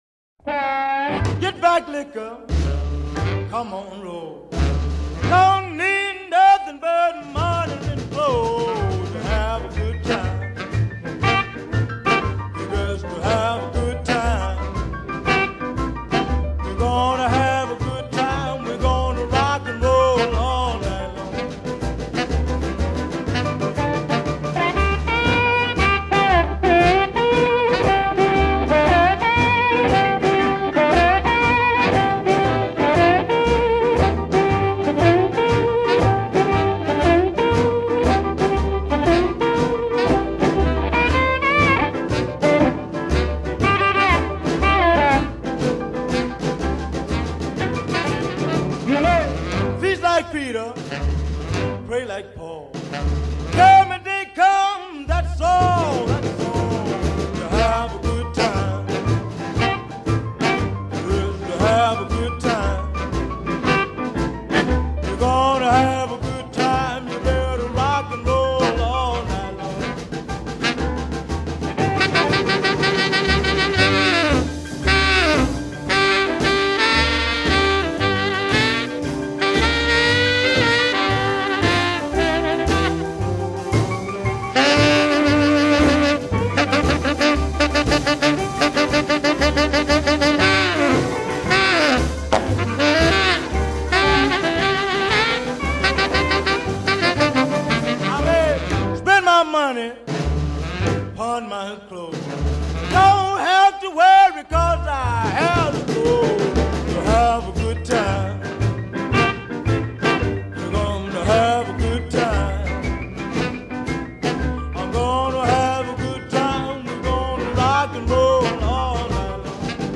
harmonica